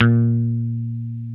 Index of /90_sSampleCDs/Roland L-CDX-01/BS _Rock Bass/BS _Dan-O Bass